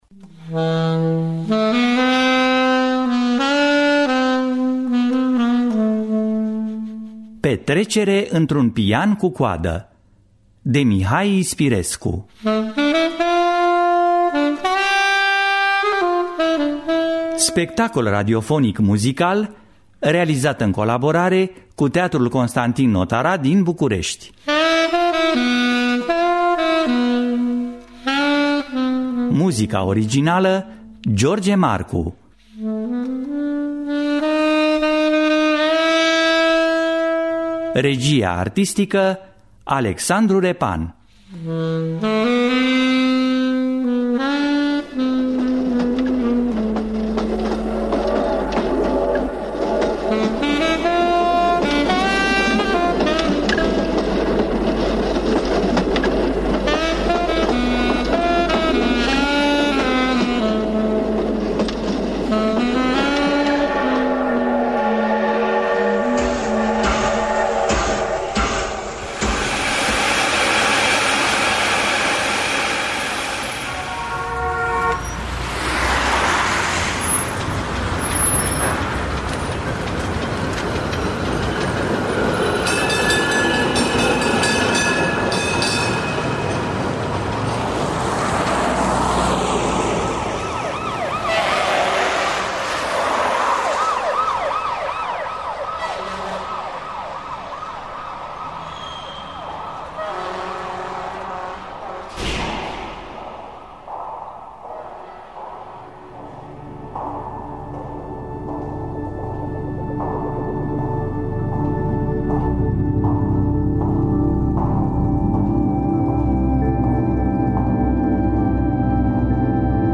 Petrecere într-un pian cu coadă de Mihai Ispirescu – Teatru Radiofonic Online